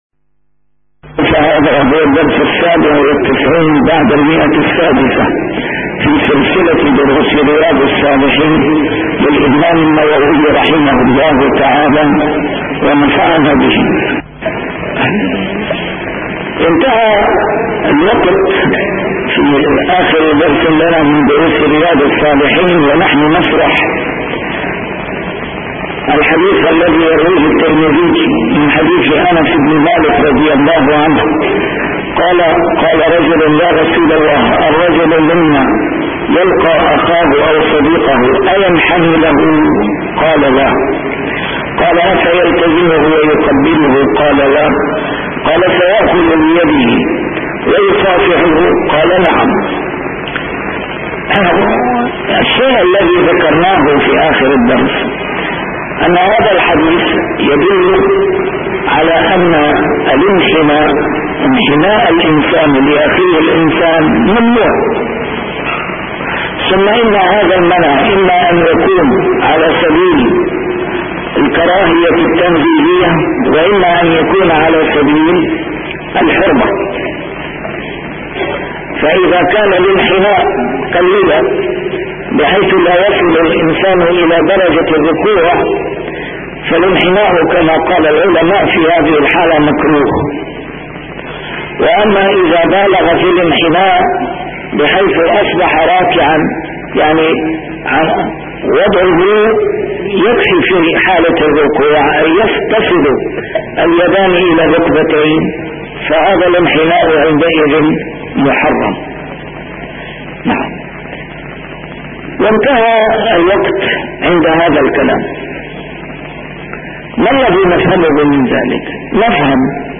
A MARTYR SCHOLAR: IMAM MUHAMMAD SAEED RAMADAN AL-BOUTI - الدروس العلمية - شرح كتاب رياض الصالحين - 697- شرح رياض الصالحين: المصافحة عند اللقاء